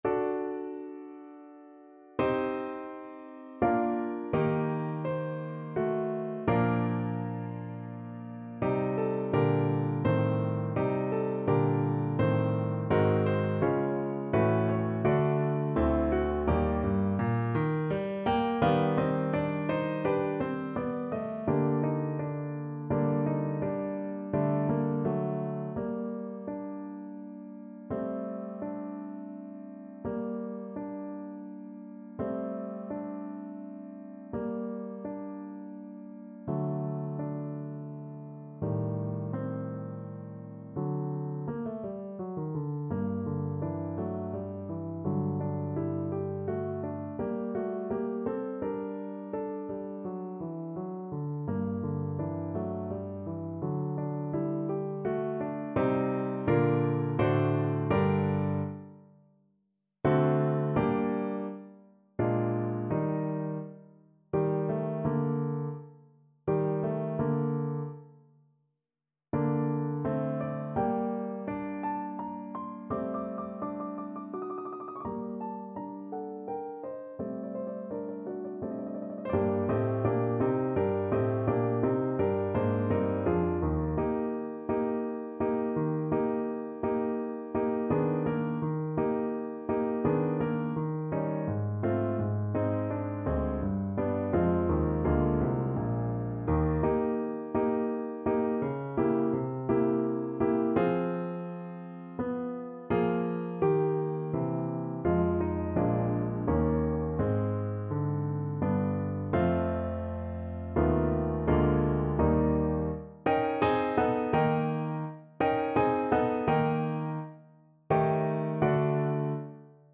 3/4 (View more 3/4 Music)
G minor (Sounding Pitch) (View more G minor Music for Violin )
Andante =84
Classical (View more Classical Violin Music)